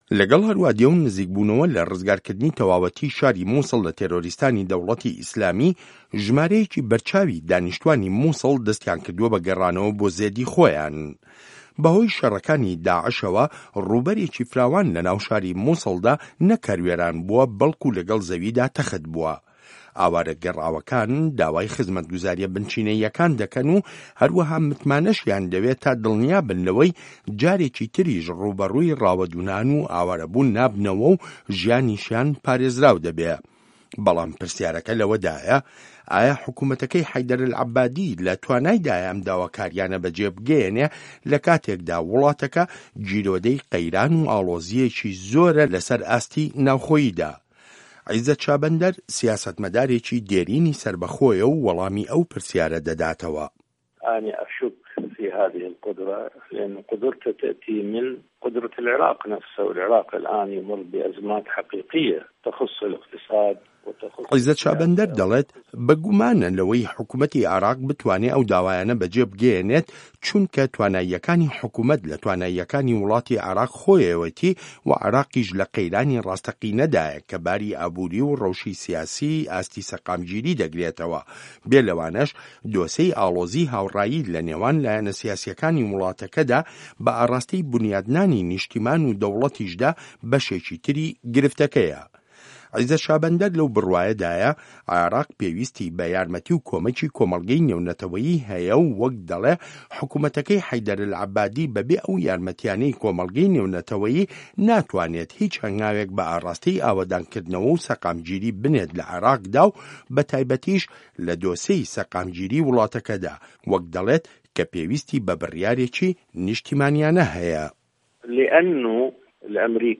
ڕاپۆرت لەسەر بنچینەی لێدوانەکانی عیزەت شابەندەر